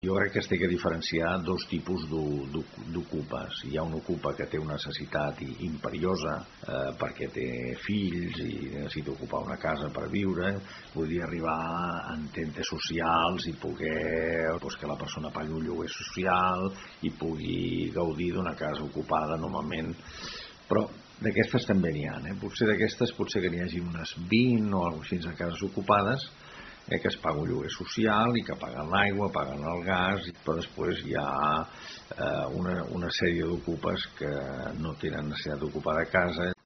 Són declaracions del regidor de governació, Rafael Cubarsí.